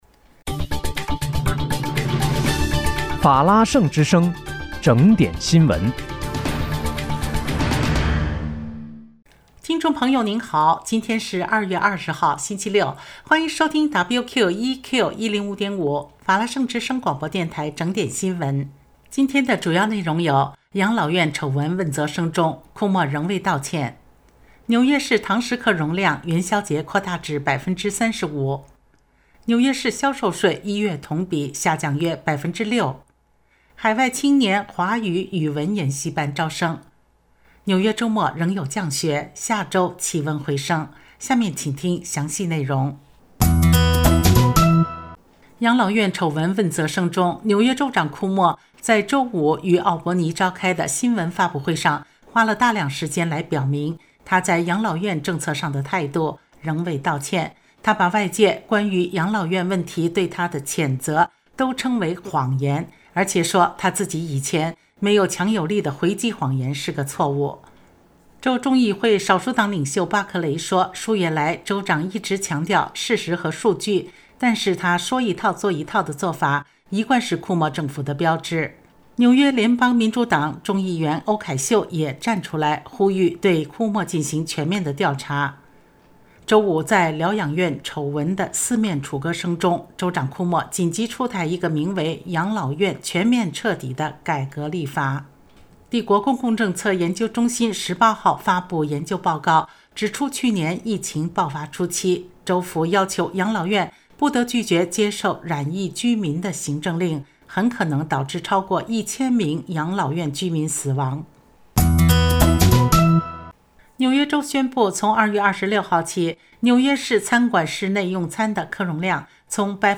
2月20日（星期六）纽约整点新闻